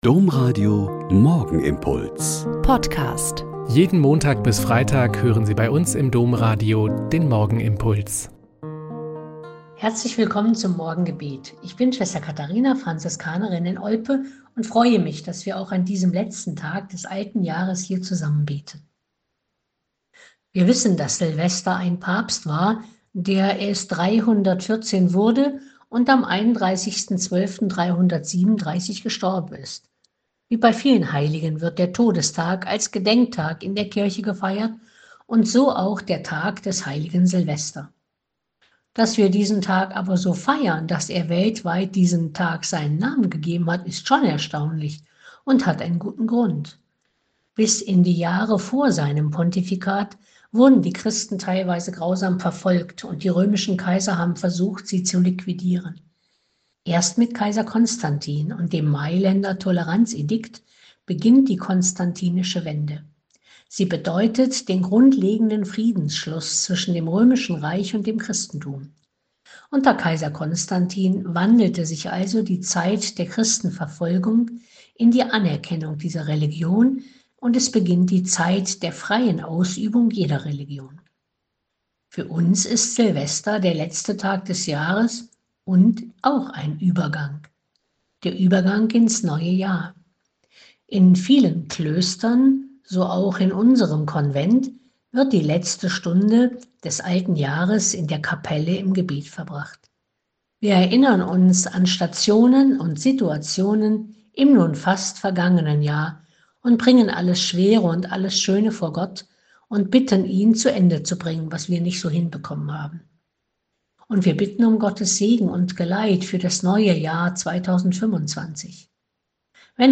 Joh 1,1-18 - Gespräch mit Dr. Tanja Kinkel - 31.12.2024